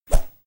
sfx_woosh_1.mp3